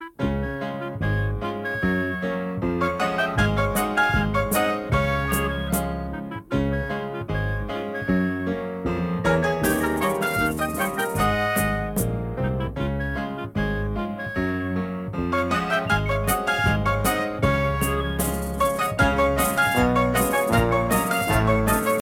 벨소리